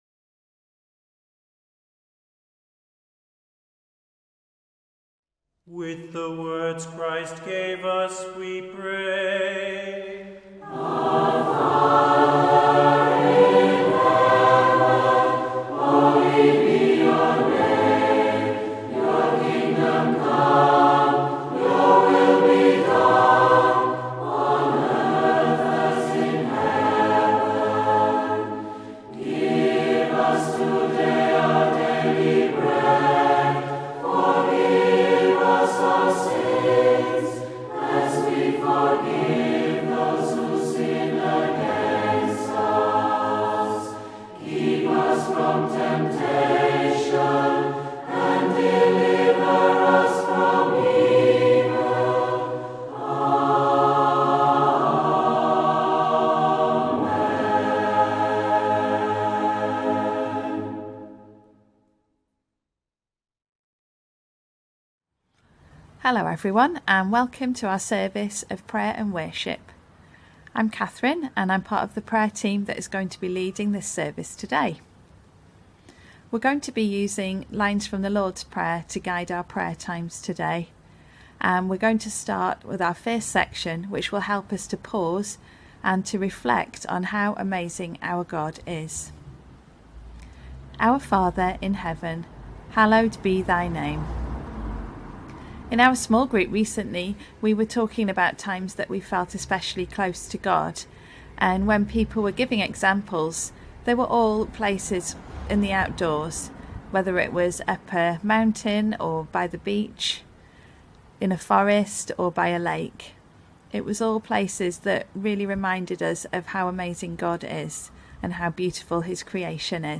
Pre-recorded video and audio.
Morning Service